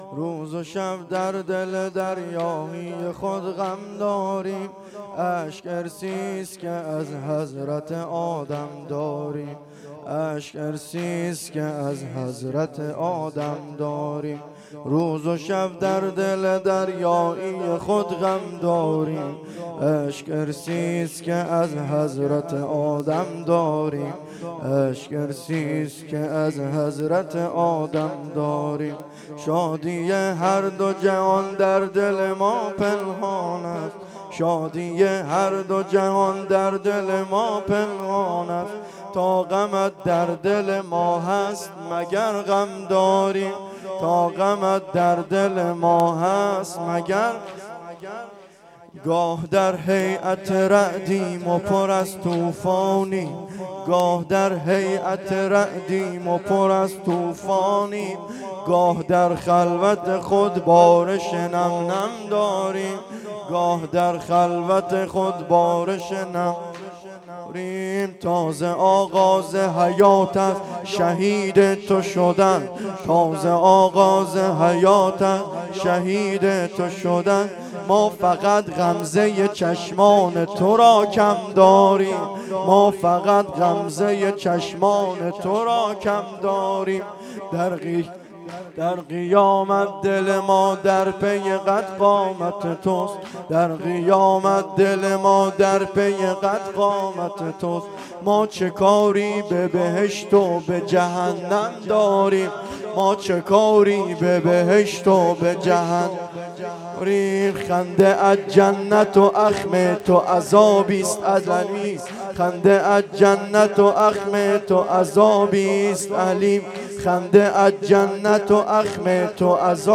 واحد شور
مراسم هفتگی